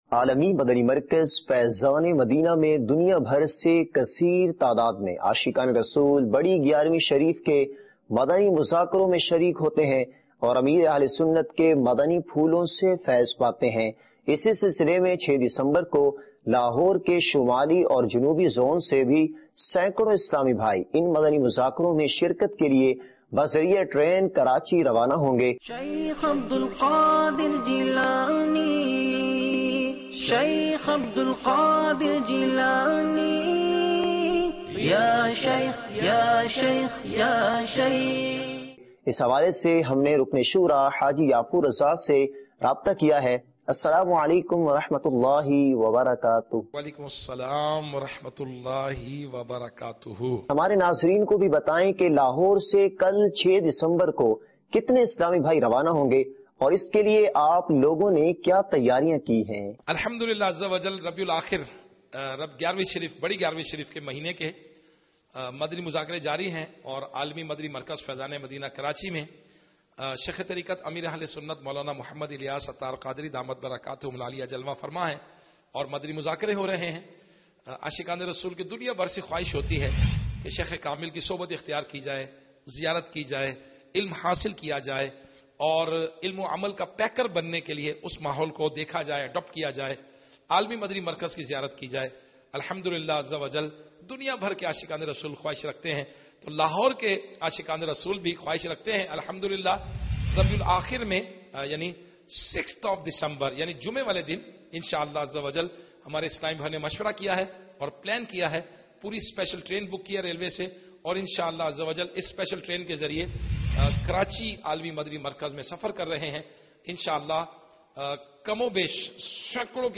News clip Urdu - 05 December 2019 - Aashiqan e Rasool Ki Giyarvi Sharif Kay Moqey Par Karachi Faizan e Madina Main Hazri Jan 16, 2020 MP3 MP4 MP3 Share عاشقانِ رسول کی گیارویں شریف کے موقع پر کراچی فیضان مدینہ میں حاضری